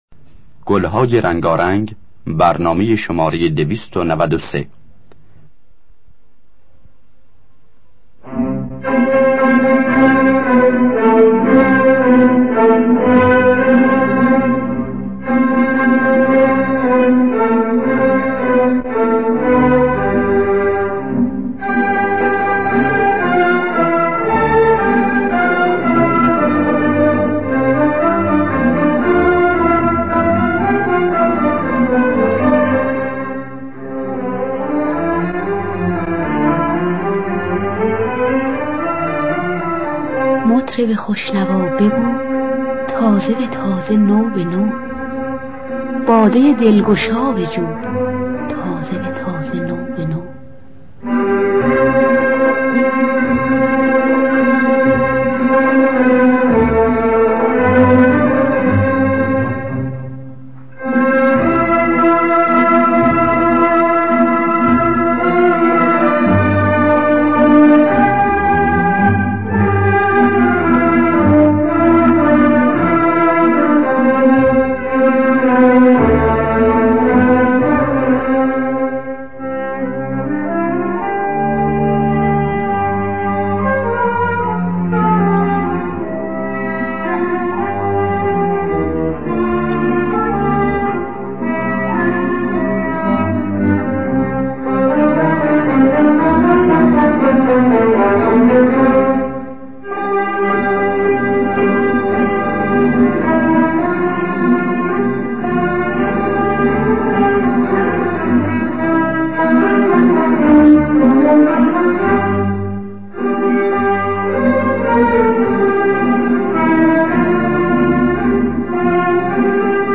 گلهای رنگارنگ ۲۹۳ - ماهور
خوانندگان: مرضیه حسین قوامی نوازندگان: جواد معروفی روح‌الله خالقی مرتضی محجوبی